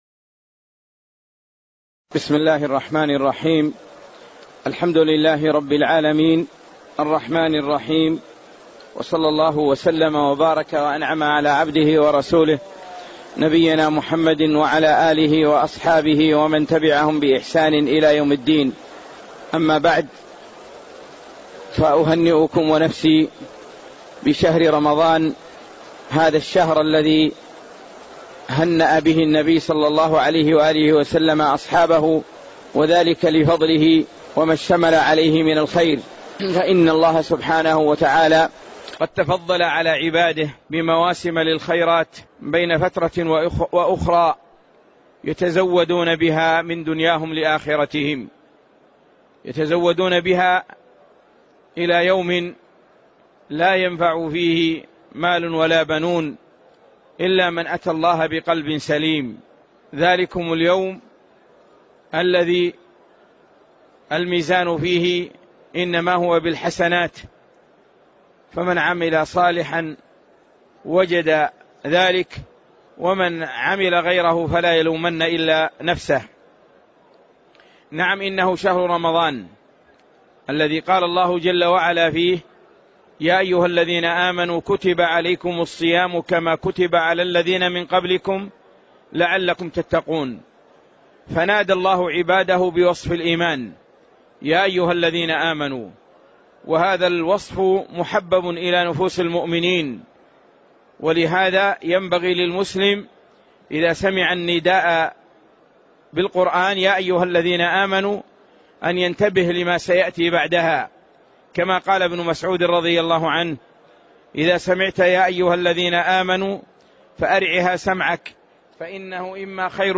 محاضرة - فضائل شهر رمضان